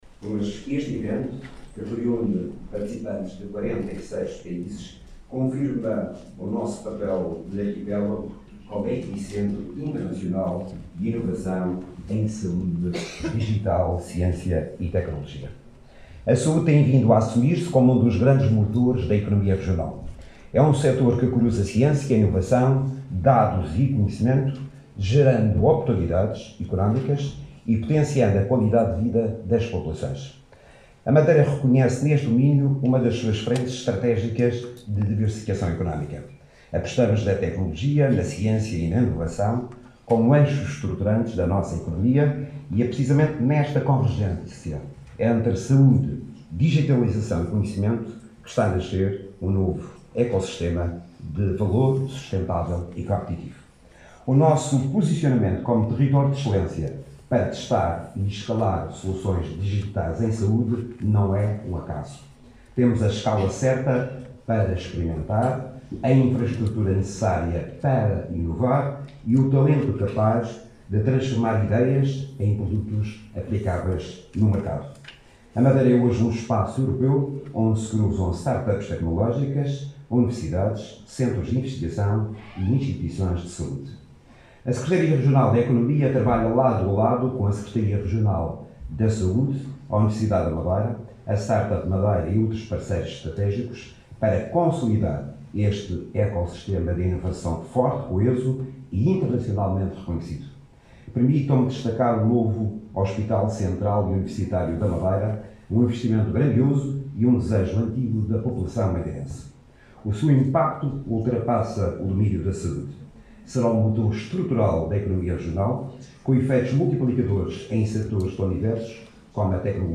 José Manuel Rodrigues falava no arranque dos trabalhos, de hoje, da cimeira Digital Health Summit 2025, que reúne no Funchal participantes de 46 países.